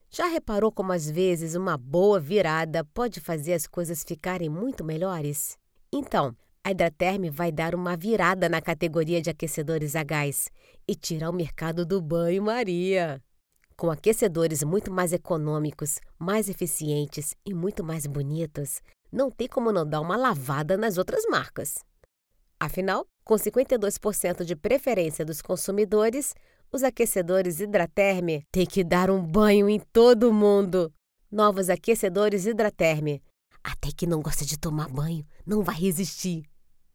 I have a natural and versatile voice, free from vices or exaggerations, with neutral Brazilian Portuguese suitable for different age groups and styles.
I have an isolated home studio treated with professional equipment: AT2020 mic and Focusrite Scarlett card.
Sprechprobe: Industrie (Muttersprache):